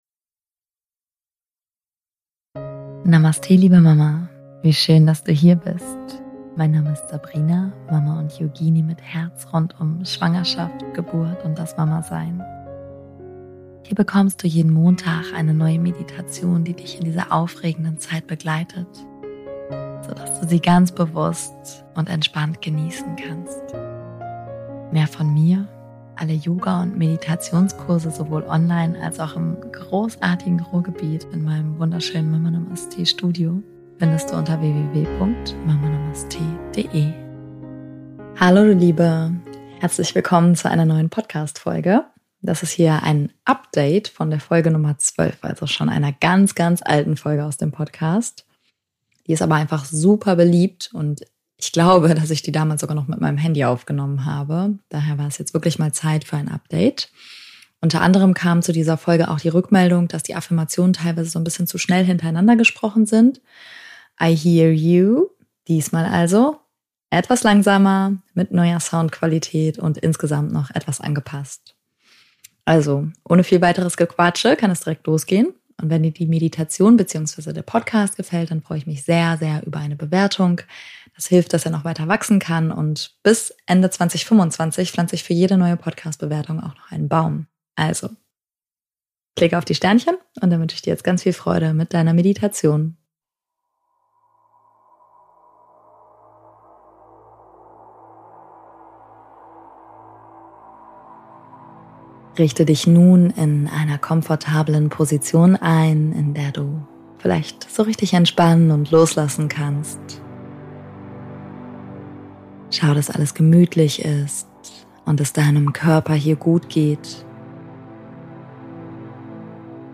Jetzt war es aber wirklich an der Zeit für eine Neuauflage: mit besserem Sound, etwas mehr Ruhe zwischen den Affirmationen und kleinen inhaltlichen Anpassungen. In dieser Meditation geht es um die liebevolle Verbindung zu deinem Baby in der Schwangerschaft – und darum, wie du dich innerlich auf deine Geburt vorbereiten kannst.